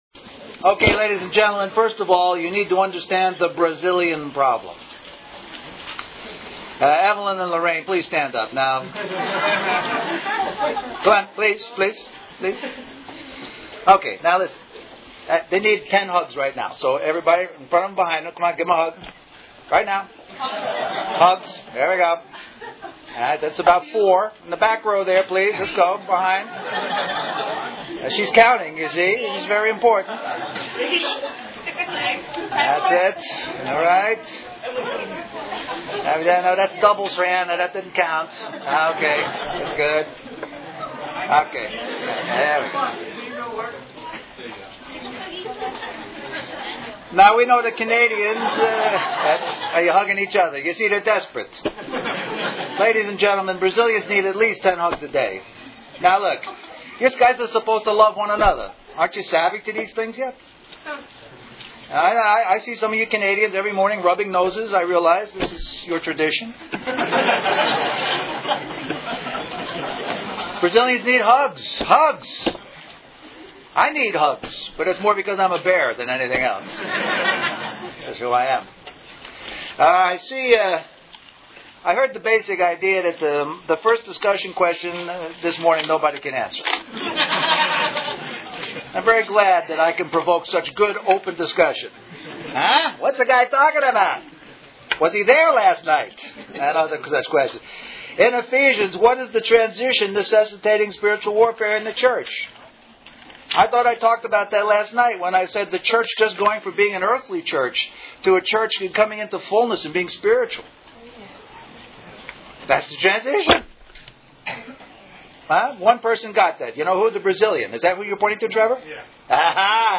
Toronto Summer Youth Conference